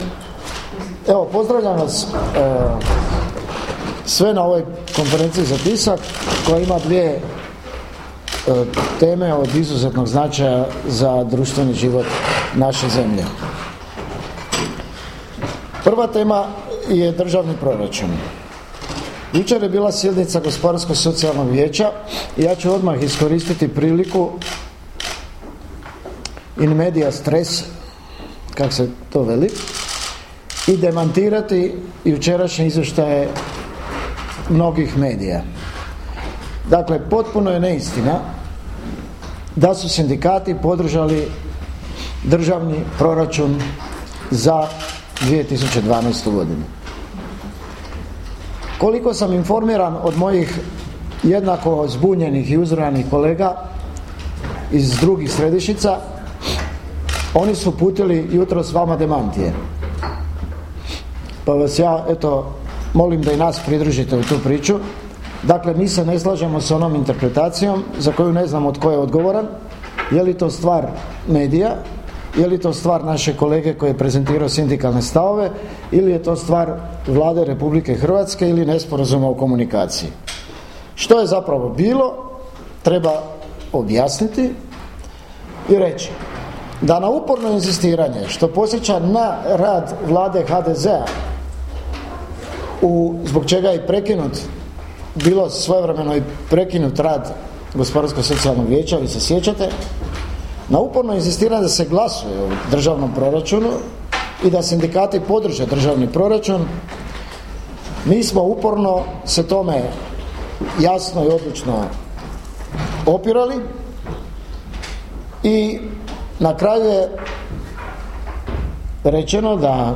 Konferencija za tisak MHS o Državnom proračunu i sudbini Vjesnika
Matica hrvatskih sindikata održala je jučer u 10 sati u prostorijama Hrvatskog šumarskog društva u Zagrebu tiskovnu konferenciju o dvije društveno važne teme: prijedlogu Državnog proračuna za 2012. godinu te o sudbini dnevnog lista Vjesnik.